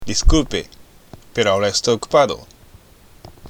（ディスクルペ　ペロアオラ　エストイ　オクパード）